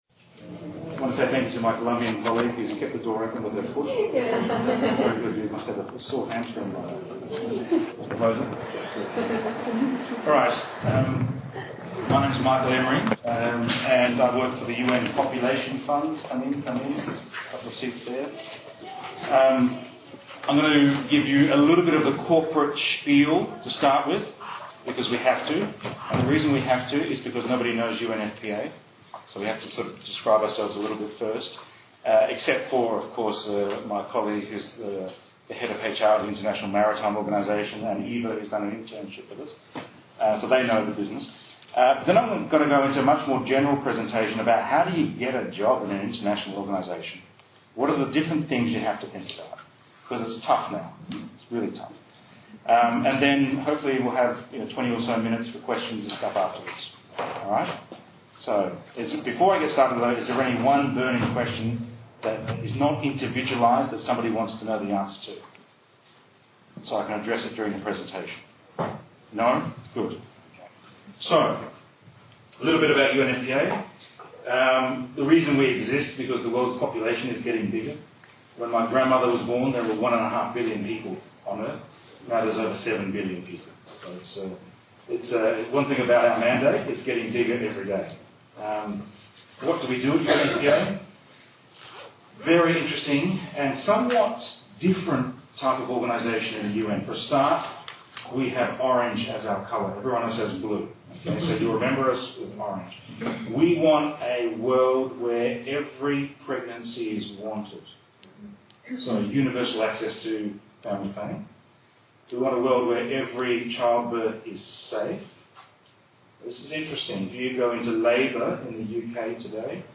Careers presentation from the UN Population Fund (UNFPA)
This talk was delivered at the'International Organisations Careers Fair' in London on December 14, 2013.